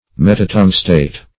Metatungstate \Met`a*tung"state\, n.